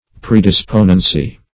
Search Result for " predisponency" : The Collaborative International Dictionary of English v.0.48: Predisponency \Pre`dis*po"nen*cy\, n. The state of being predisposed; predisposition.